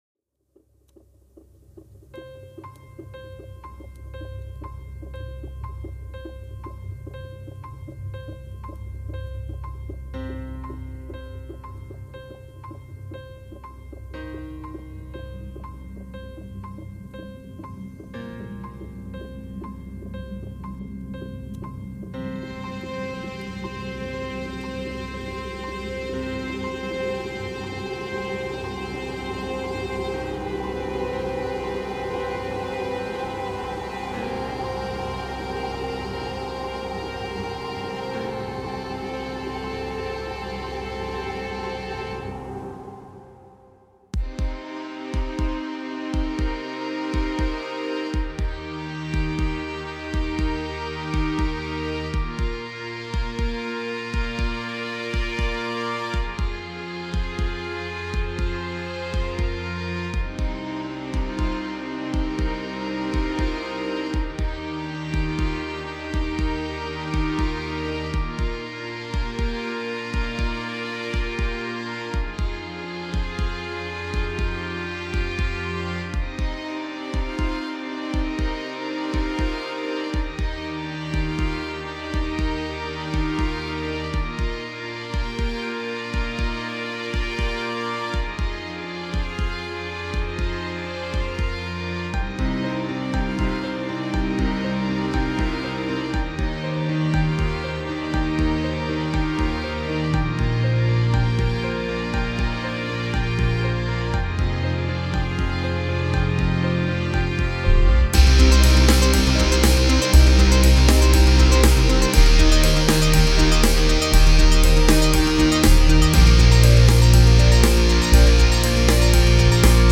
J'aime bien l'illustration du déroulement du temps, avec la partie rythmique en fond de fuite. La fin est un peu brutale à mon gout.
1: Cool, début un peu dark appréciable et suite rappelant des BO sur des plans de films sur de grands voyages/ grands paysages ou sur des timelapes:
J'ai essayé de rendre compte du temps qui passe, son côté inéluctable, inébranlable, avec un côté "acceptation" ou "résignation" face à ce temps qui s'écoule. D'où l'aspect un peu mélancolique, quasi absence de thématique forte pour laisser une place vers la linéarité.